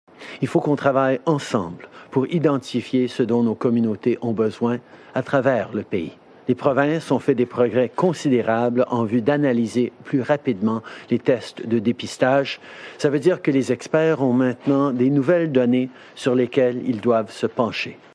C’est ce qu’il a déclaré lors de sa conférence de presse quotidienne jeudi avant-midi.